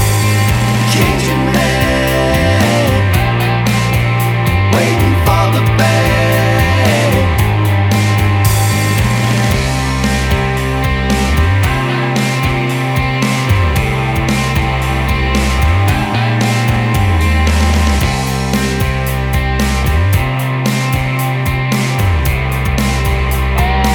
Minus Lead Guitar Pop (1990s) 3:45 Buy £1.50